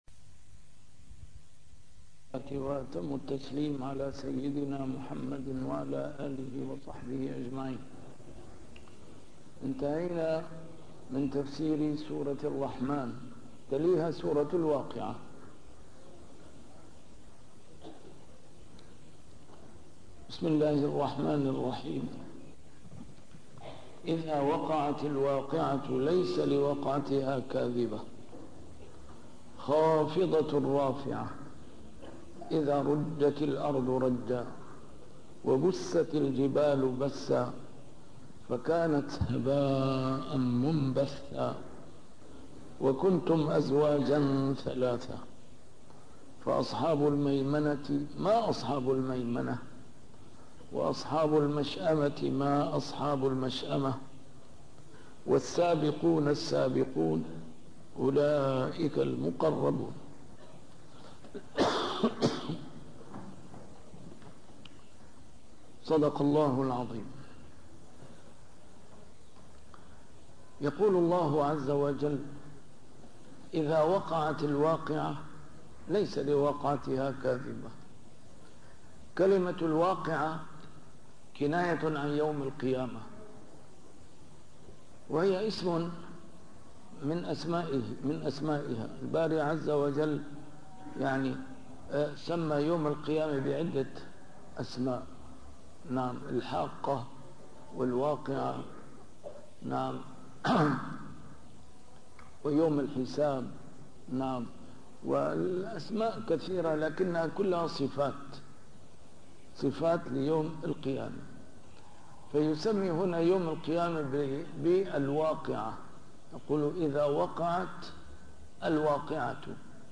A MARTYR SCHOLAR: IMAM MUHAMMAD SAEED RAMADAN AL-BOUTI - الدروس العلمية - تفسير القرآن الكريم - تسجيل قديم - الدرس 729: الواقعة 01-11